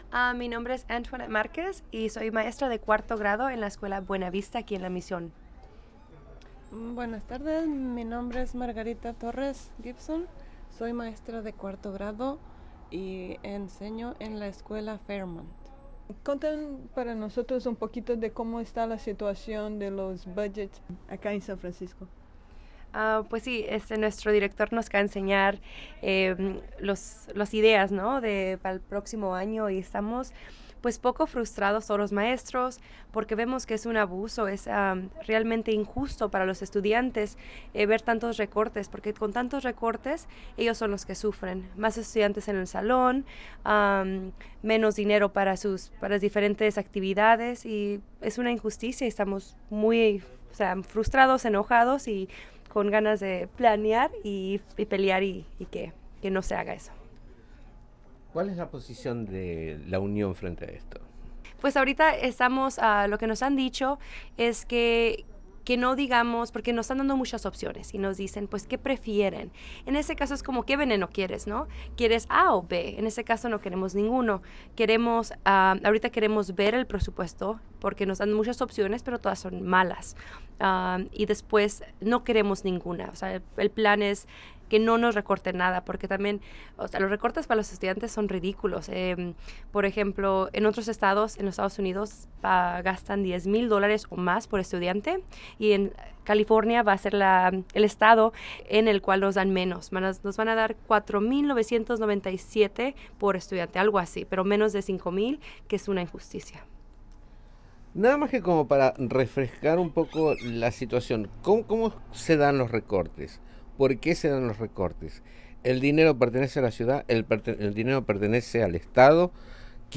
Entrevista com maestras de San Francisco sobre los cortes en el budget de la educación. Llamada para la protesta del 4 de Marzo.